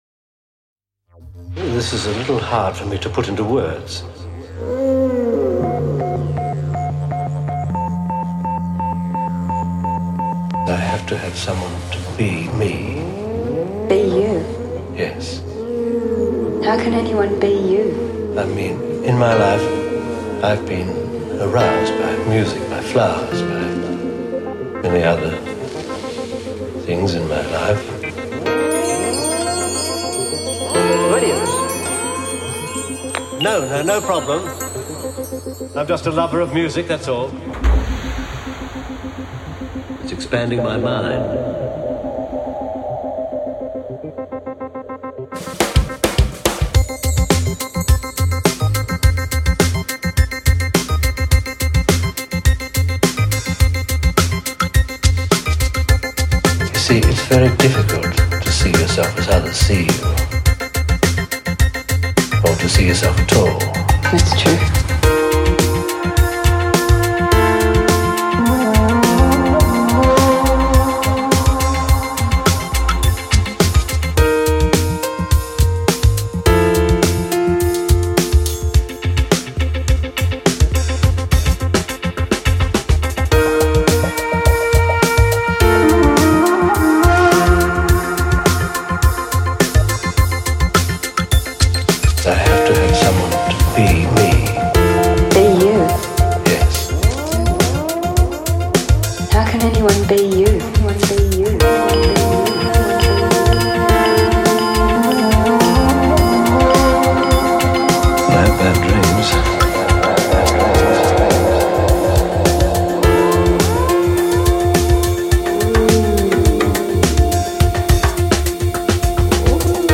Žánr: Electro/Dance
svým sytým hlasem